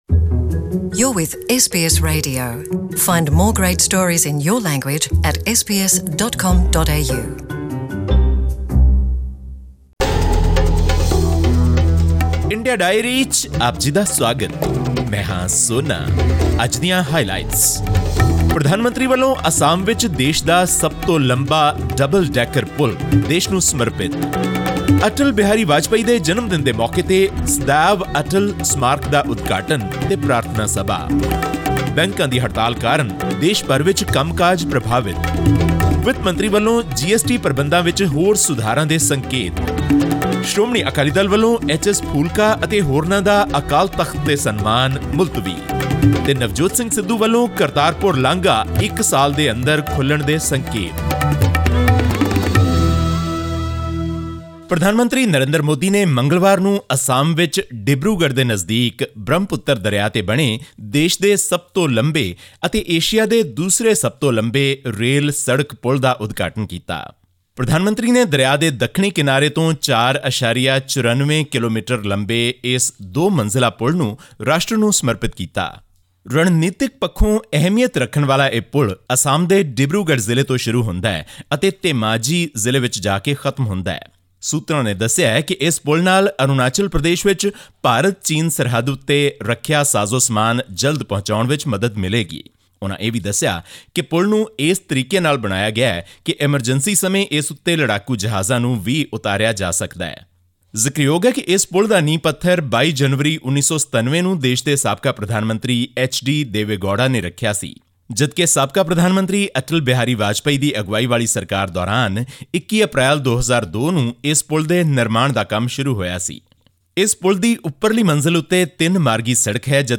Our India correspondent brings you the most important news of the week from India.